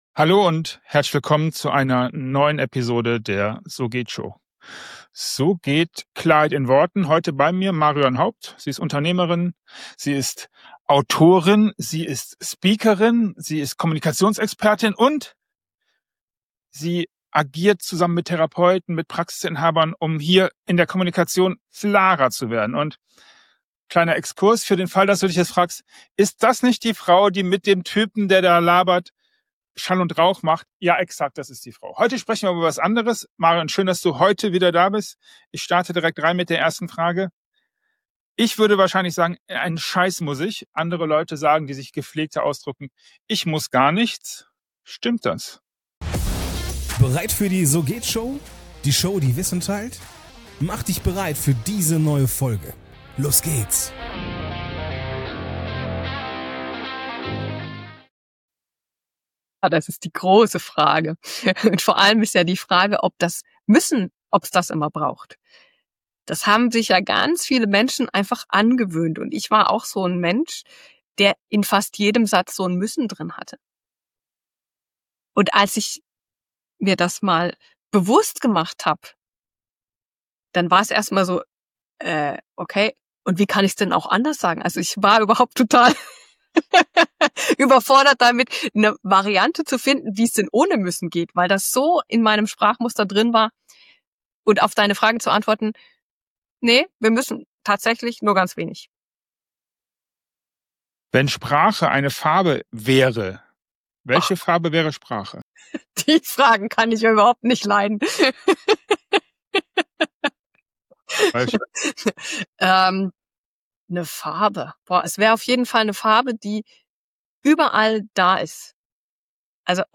Mein Interviewgast hat 45 Sekunden für seine Antwort.
Keine Nachbearbeitung, keine zweiten Versuche – was gesagt wird, bleibt.